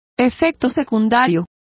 Complete with pronunciation of the translation of aftereffect.